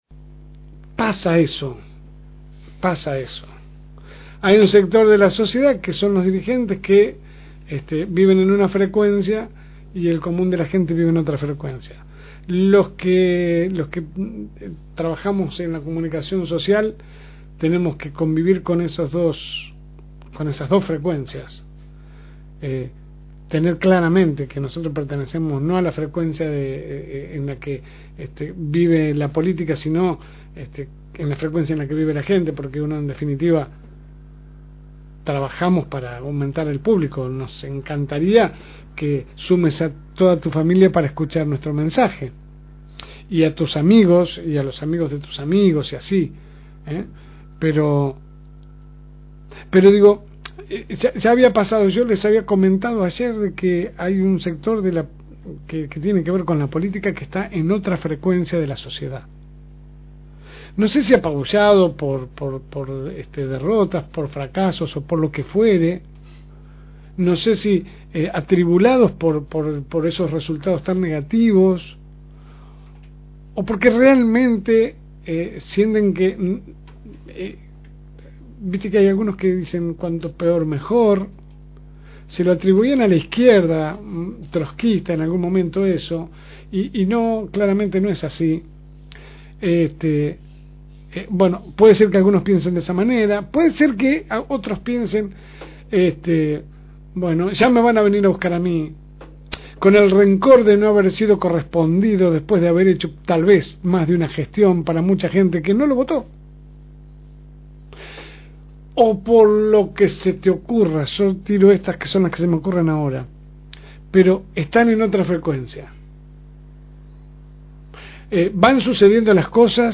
AUDIO – Editorial de la LSM. – FM Reencuentro